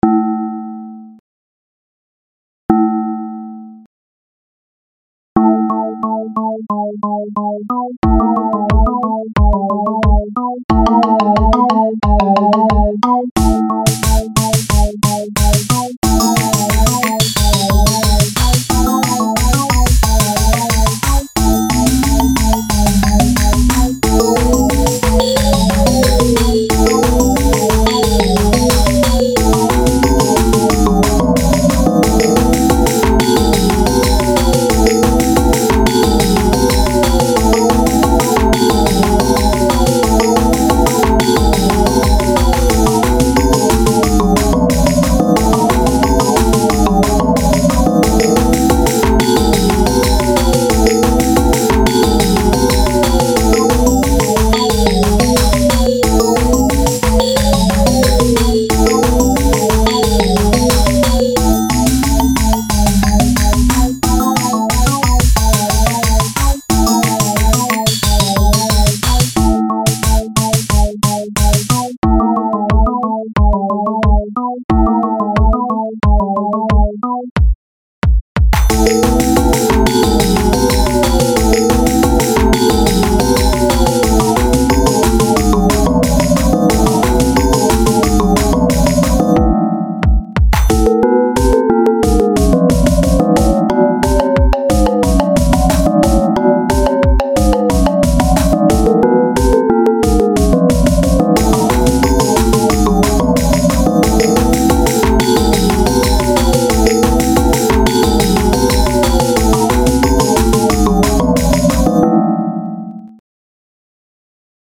Seriously though, it's got great diversity in the melody, the breaks really help shuffle it up.. good instrumentation, lots of things going on in the melody and the background.
Sounds a bit... MIDI-ish.
I know you used FL to make that, those starting instruments I know from the top of my head Laughing (The Kick, Snare and Hat I mean).
Of course, the song is still lacking structure among other things, but for now I suggest you simply play around for a while, making funny tunes, I'll get to the nitpicking once you've grown your skills a little.